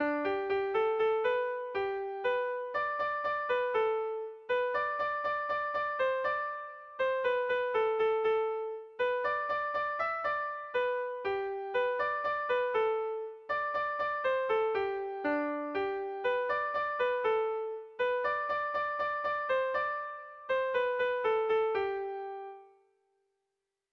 Kontakizunezkoa
Hamarreko txikia (hg) / Bost puntuko txikia (ip)
A1BDA2B